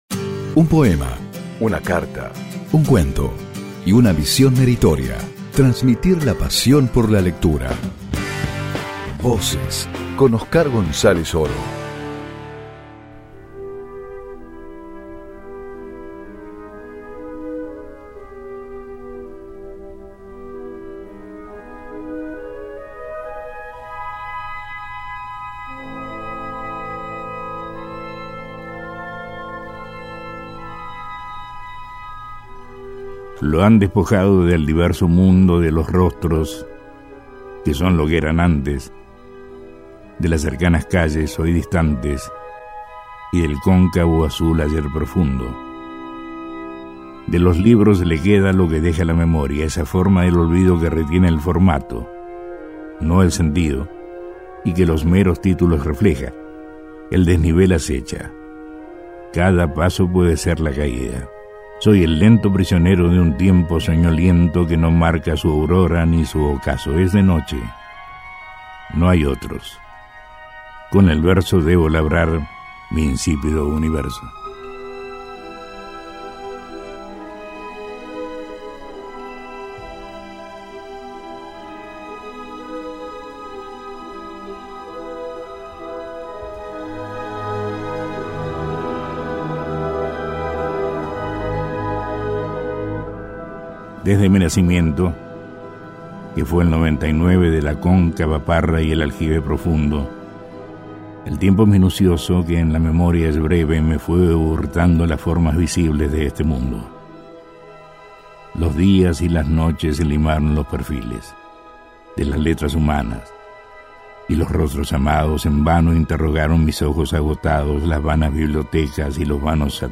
Oscar González Oro lee lo mejor de Jorge Luis Borges en Voces